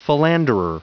Prononciation du mot philanderer en anglais (fichier audio)
Prononciation du mot : philanderer